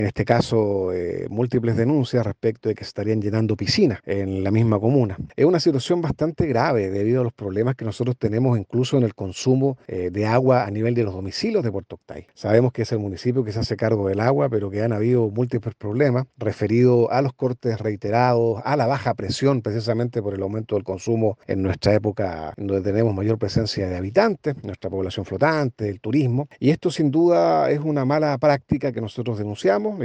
Se realizó una denuncia sobre el mal uso de un camión municipal, en una de las recientes sesiones del concejo municipal de Puerto Octay.